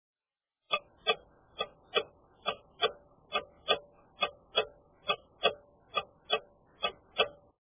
时钟走动音效 滴答声
【简介】： 时钟走动的声音、钟声音效、滴答声